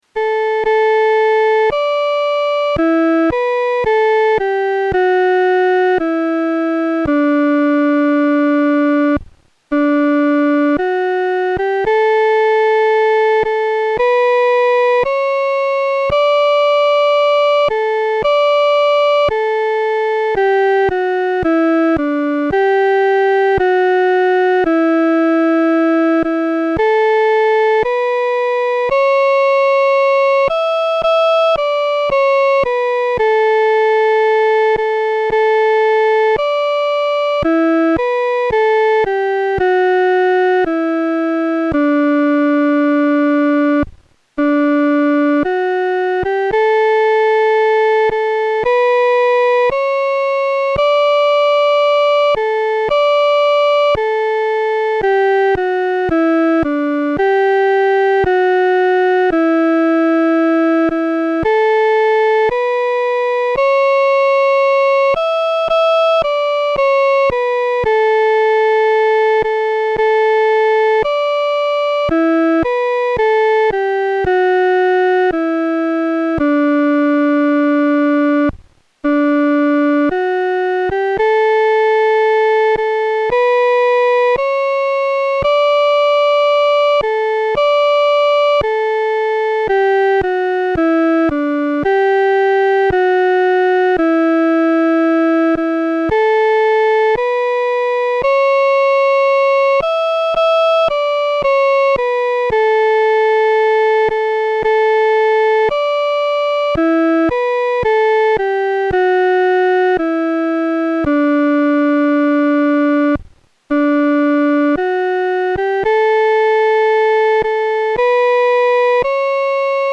伴奏
女高
本首圣诗由石家庄圣诗班录制